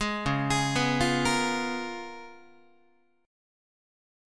C#13 chord